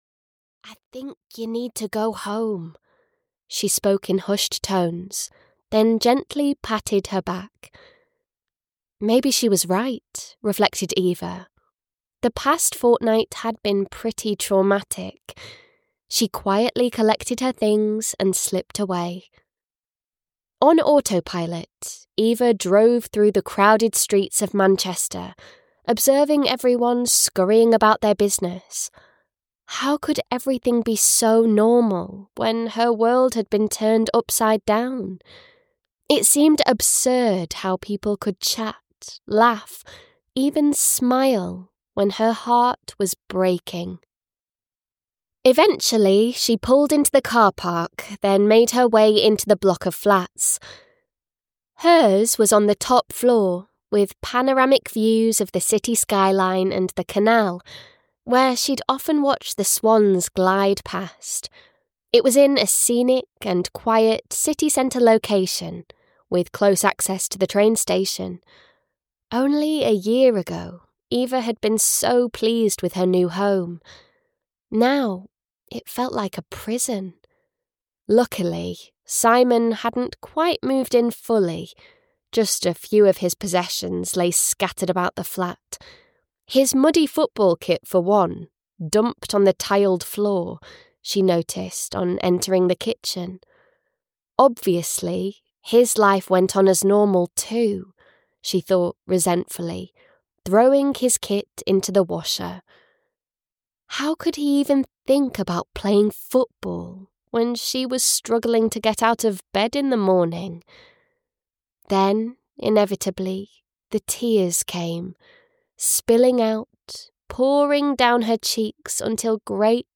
Together in Lilacwell (EN) audiokniha
Ukázka z knihy
together-in-lilacwell-en-audiokniha